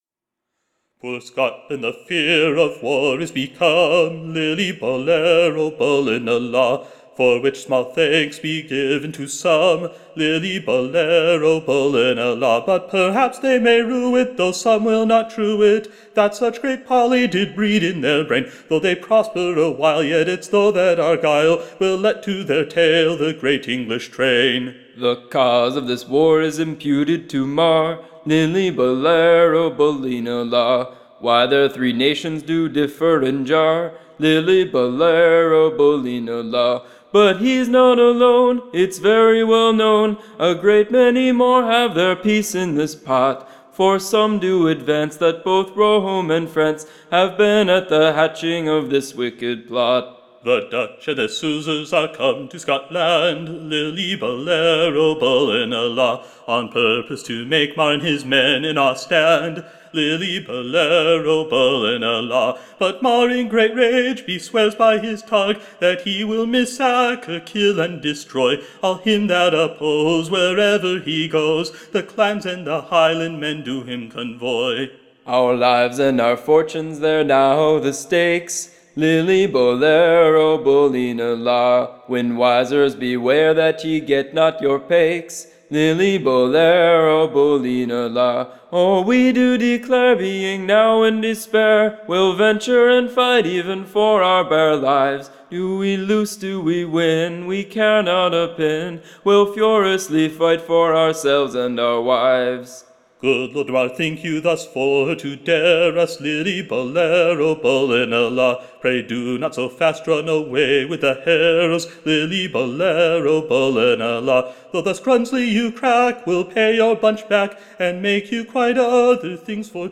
EBBA 34317 - UCSB English Broadside Ballad Archive